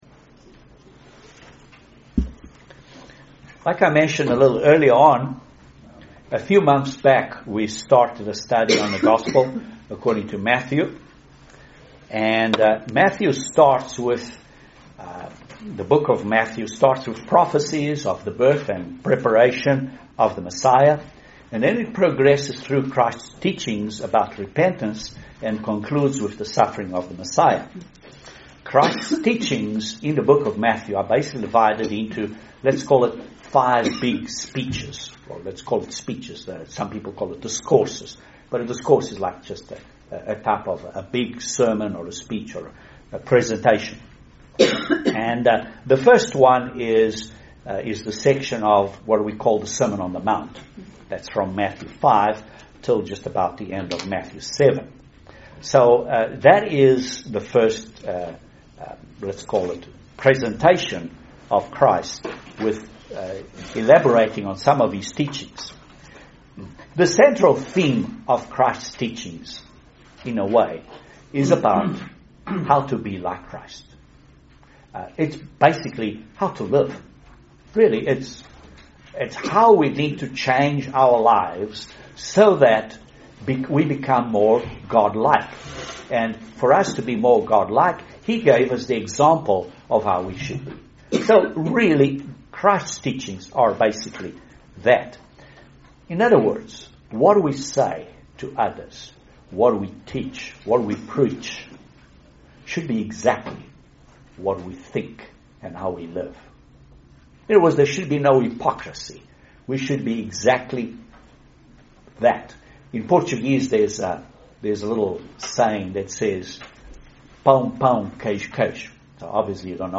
Bible Study Matthew 5 - 5-6